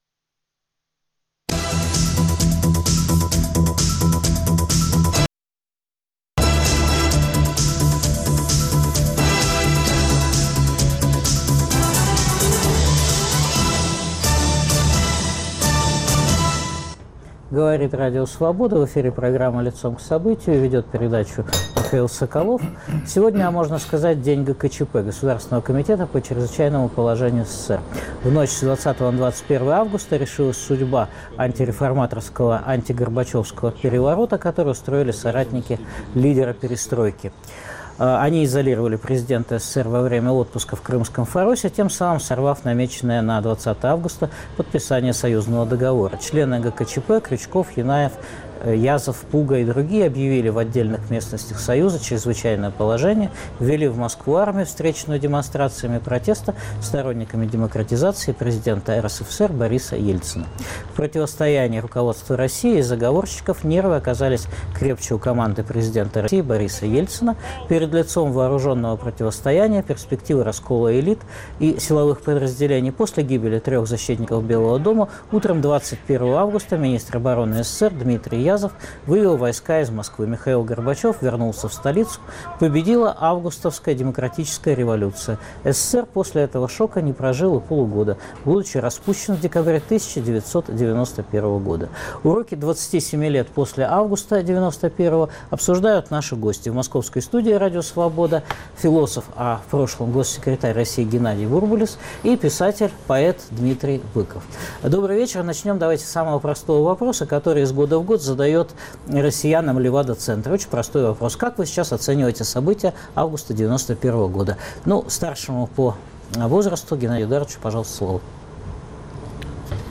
Уроки 27 лет прошедших после Августа 1991 года обсуждают бывший госсекретарь Российской федерации, философ , глава фонда "Стратегия" Геннадий Бурбулис и писатель, поэт Дмитрий Быков.